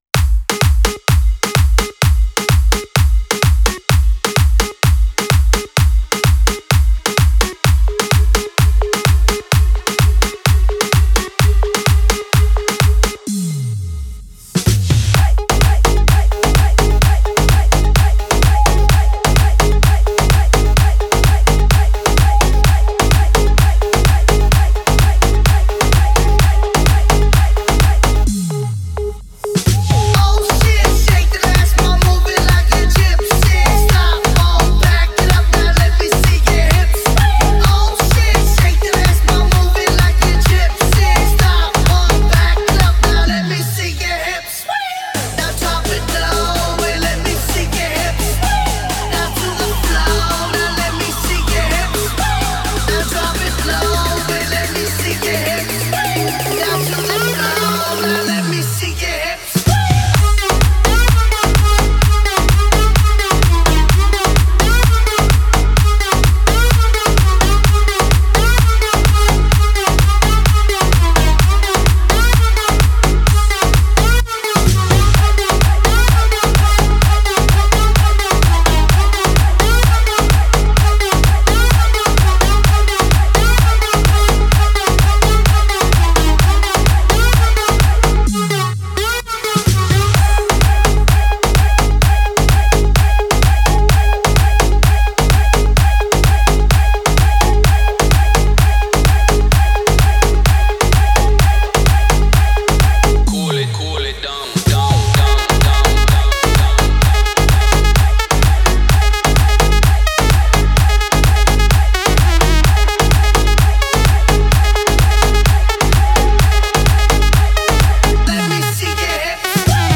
это энергичная и зажигательная композиция в жанре хип-хоп